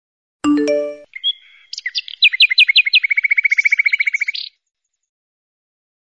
Nada Notifikasi WA Suara Burung
Keterangan: Nada dering WA suara Burung, Anda bisa mengunduhnya dan menggunakannya sebagai nada dering atau suara notifikasi untuk WA dan ponsel Anda.
nada-notifikasi-wa-suara-burung-id-www_tiengdong_com.mp3